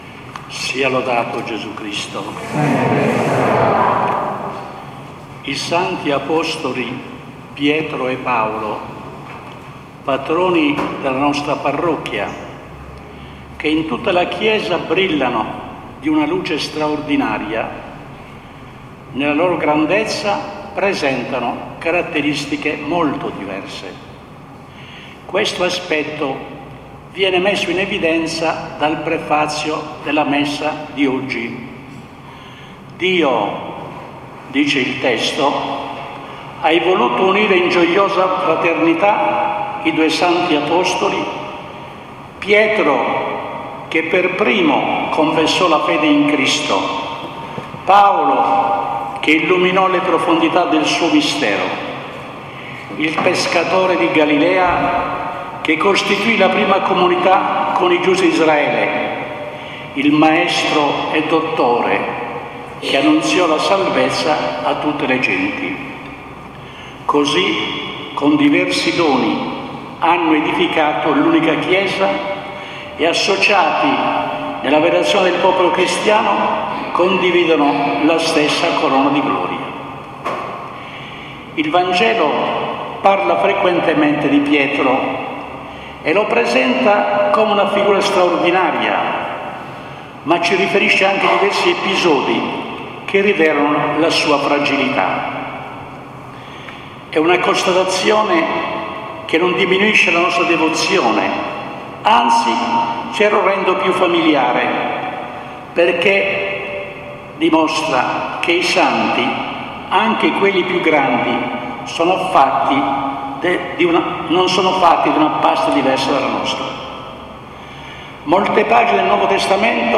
Omelia di S.E.R. Mons. Pillolla, Vescovo emerito di Iglesias, della S. Messa in occasione del terzo giorno del Triduo Patronale.